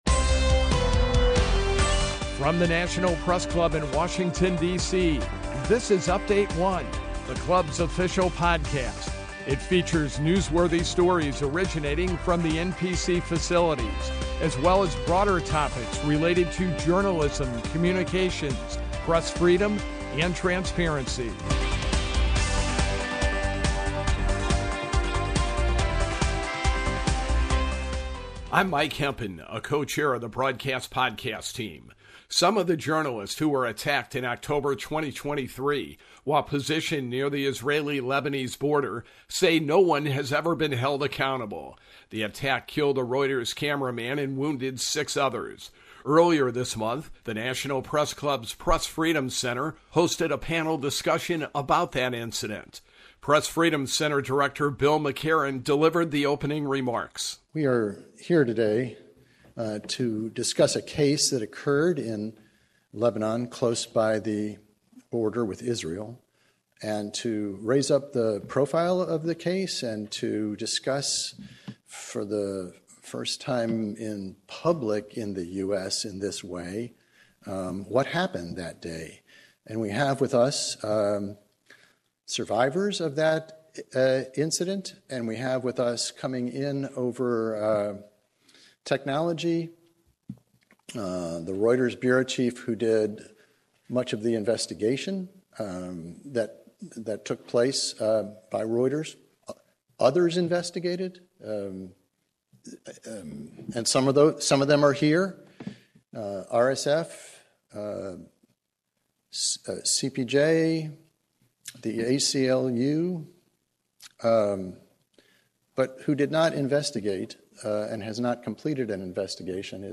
That's one of the questions surrounding the investigation into the tank shelling in October, 2023 that killed one journalist and wounded six others. This edition of Update-1 highlights the March 12 Press Freedom Center panel discussion on why no one has been held accountable for the deadly attack.
The panel includes two reporters who survived the attack and a colleague of the reporter who died.